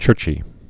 (chûrchē)